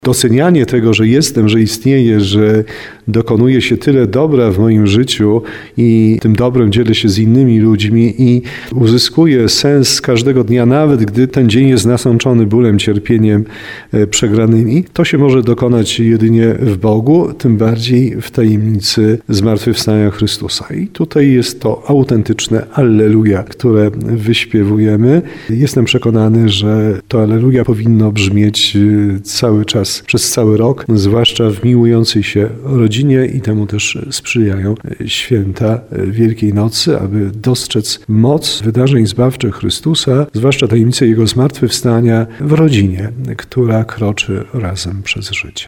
– Potrzebujemy potężnej nadziei płynącej z tajemnicy zmartwychwstania Chrystusa – mówi w wywiadzie dla Radia RDN Małopolska biskup tarnowski Andrzej Jeż.